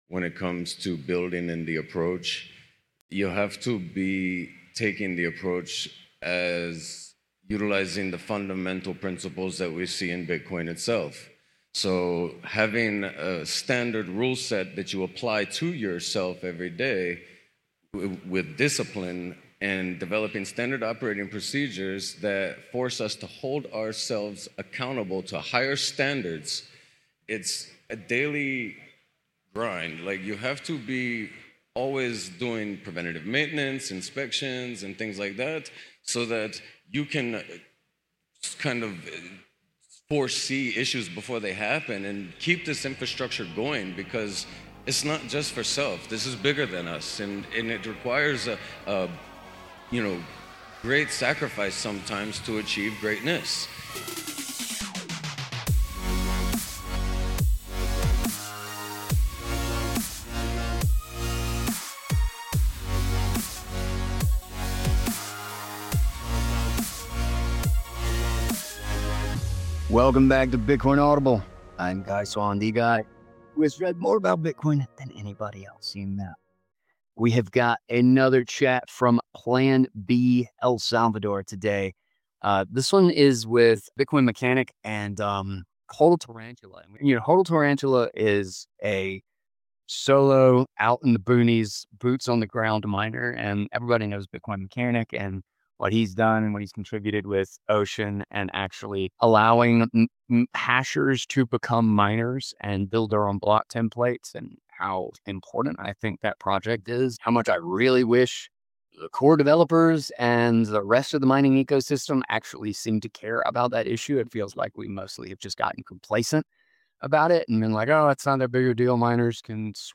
[Plan-B El Salvador]